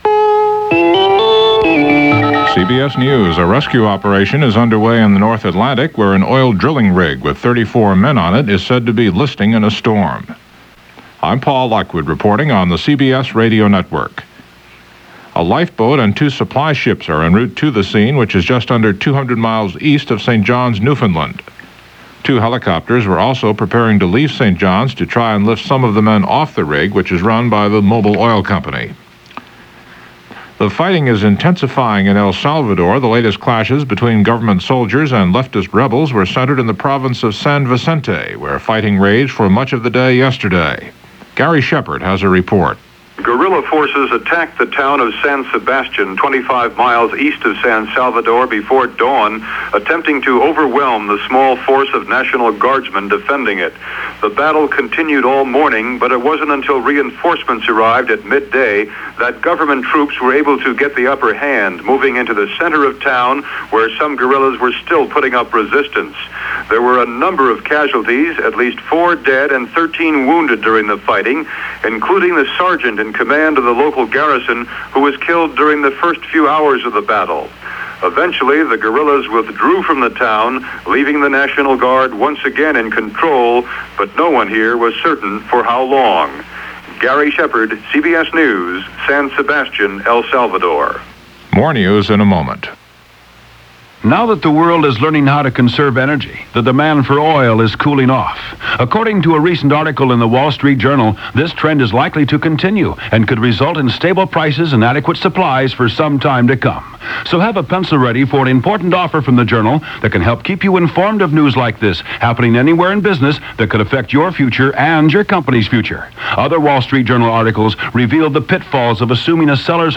And that’s a little of what went on, this February 14, 1982 as reported by CBS Radio News On The Hour.